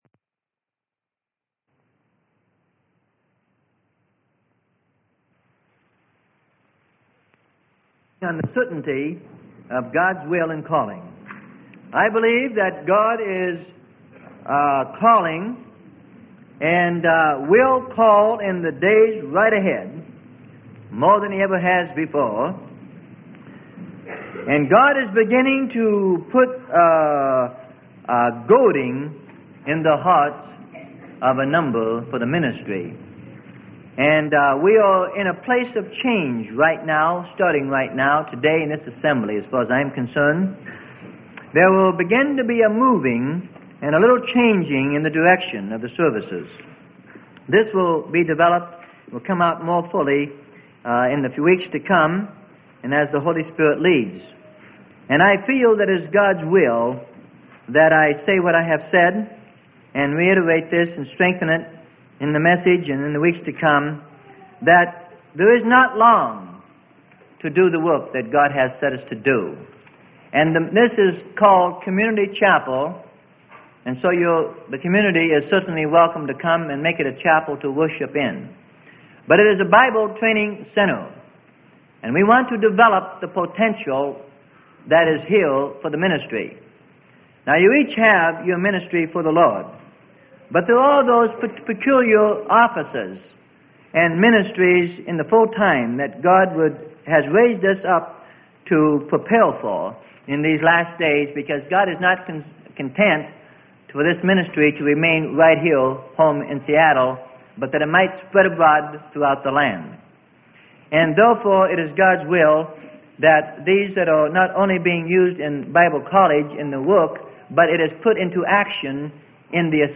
Sermon: Certainty of God's Will in Calling - Freely Given Online Library